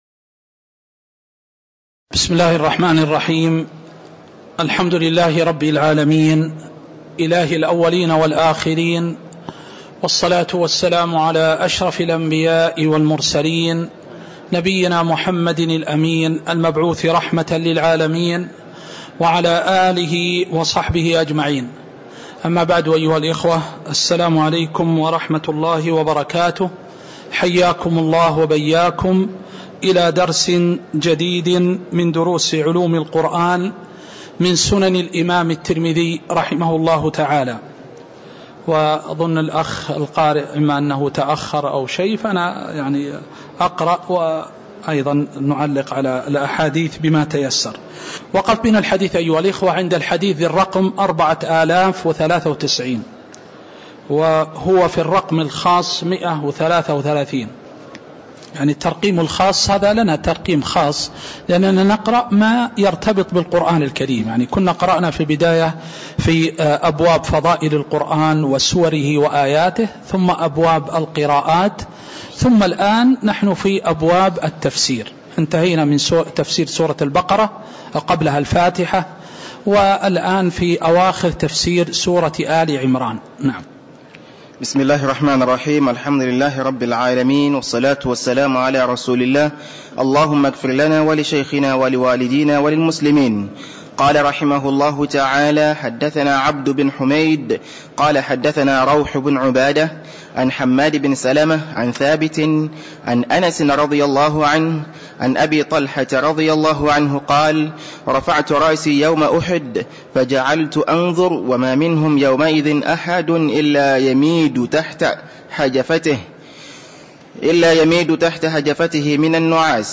تاريخ النشر ٥ ربيع الأول ١٤٤٣ هـ المكان: المسجد النبوي الشيخ